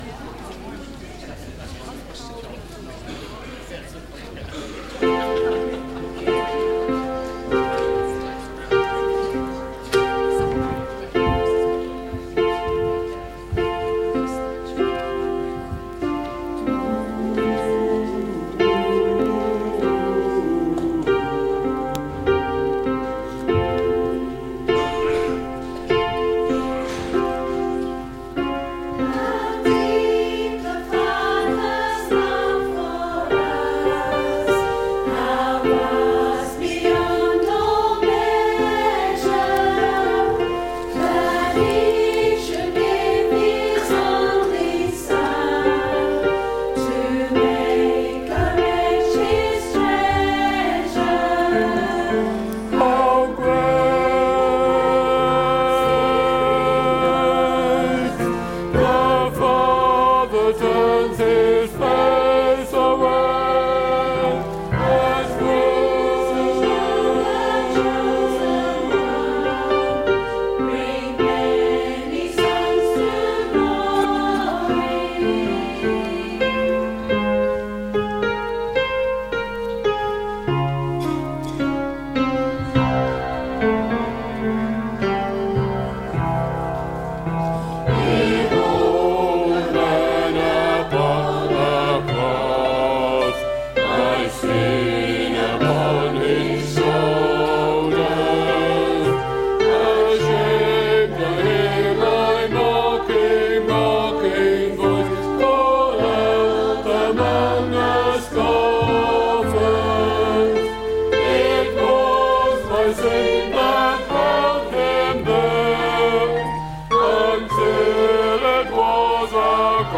Service Audio